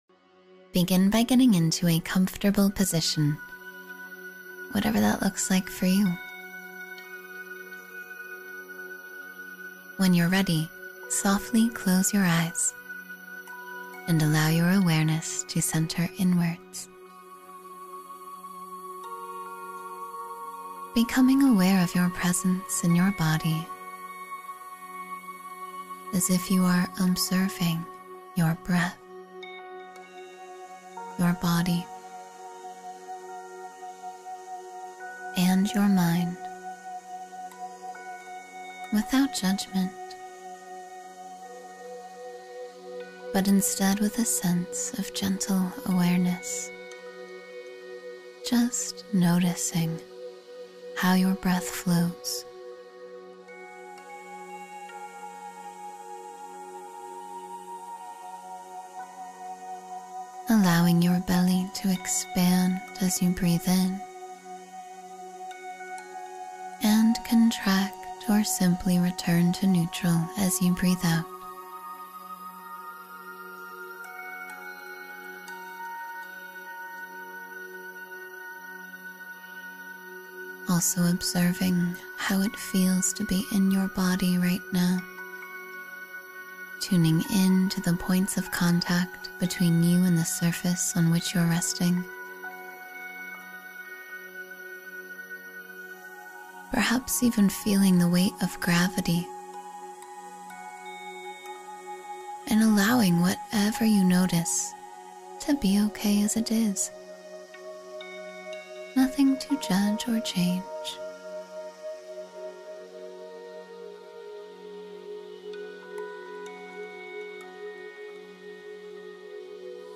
Morning Meditation to Take Charge of Your Life — Empower Your Day